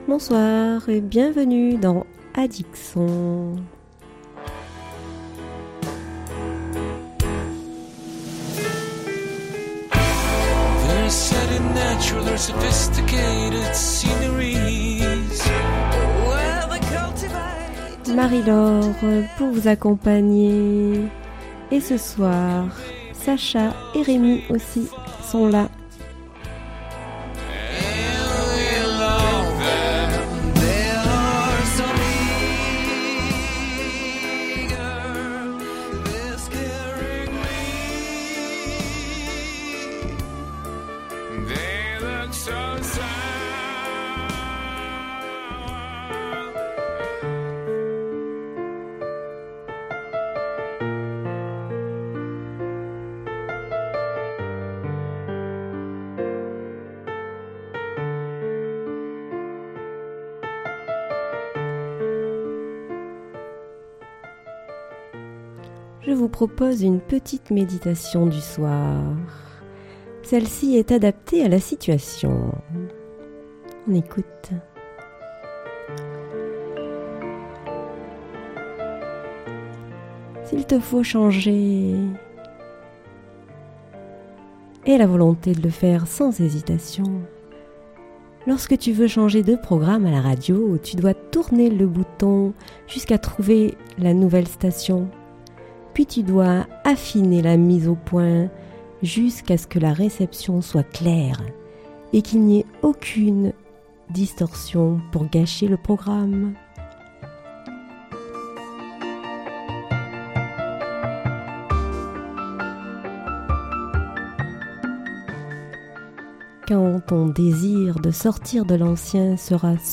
chanson française mais aussi, du blues, du rock, du reggae, du rap, jazz, etc.